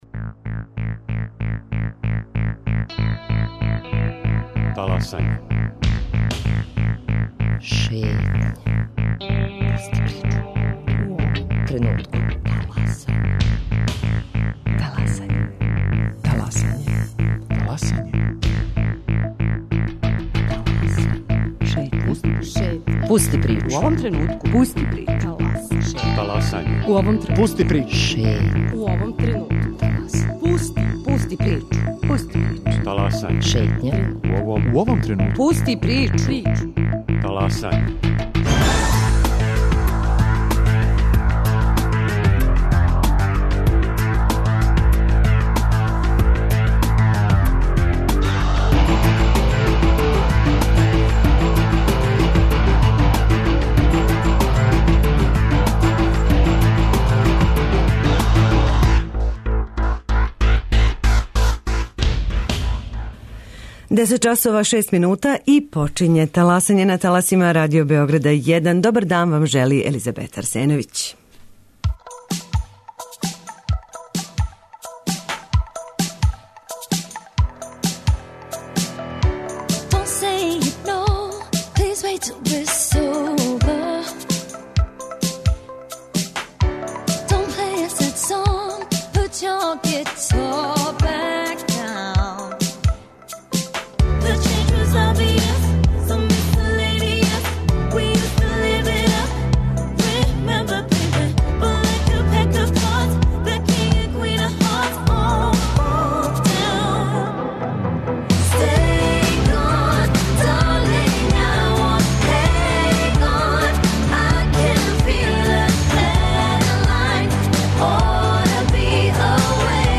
Таласање - Шетња | Радио Београд 1 | РТС